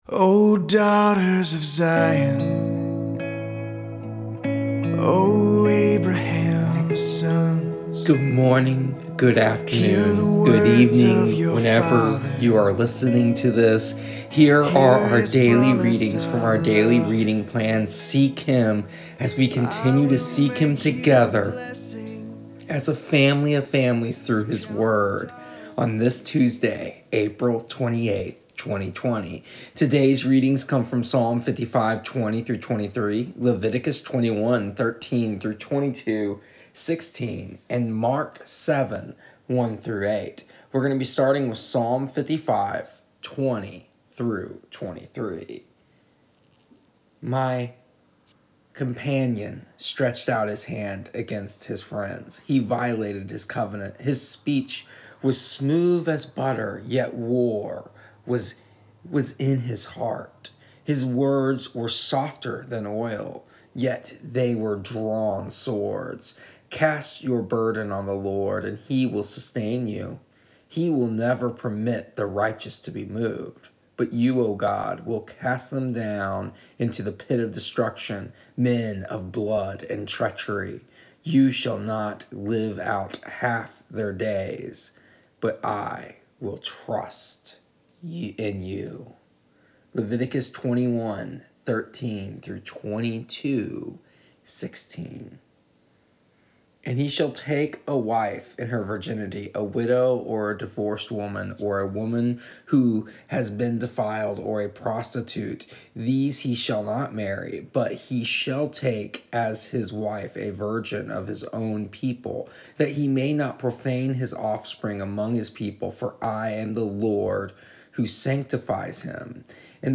Here is today’s audio readings for April 28th, 2020 from our daily reading plan Seek Him.